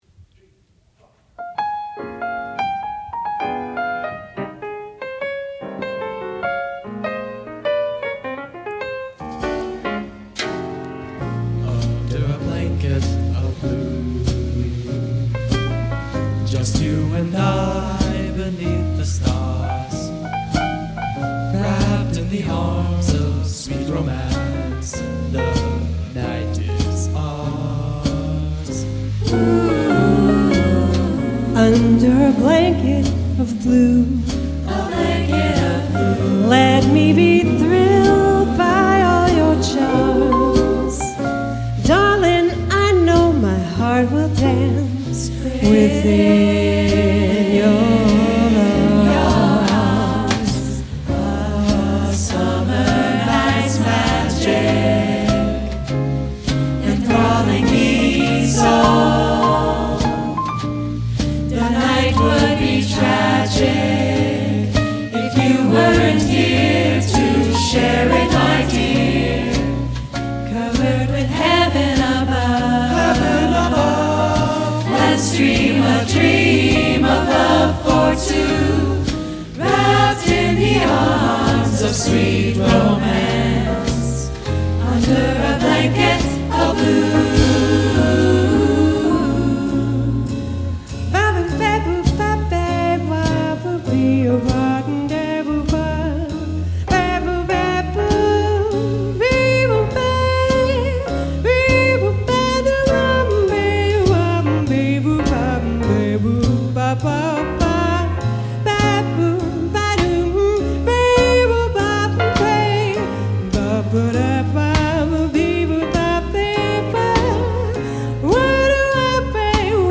S A T B /rhythm section